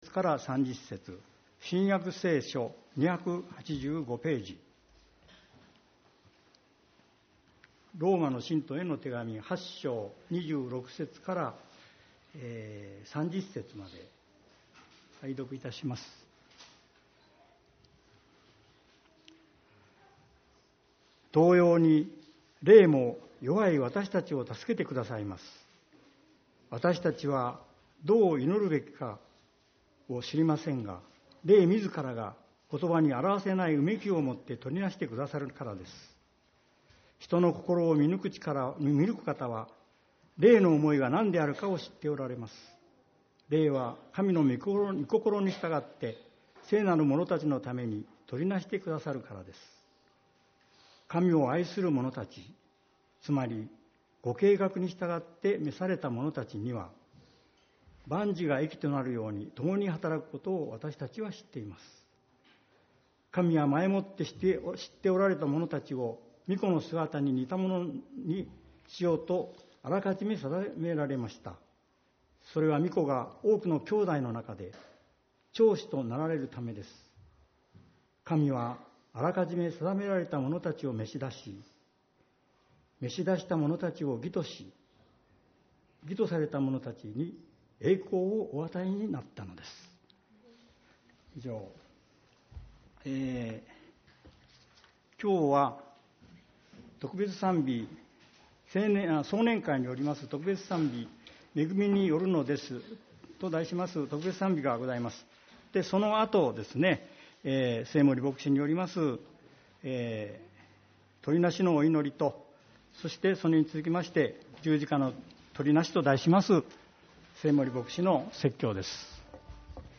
主日礼拝 「十字架の執り成し」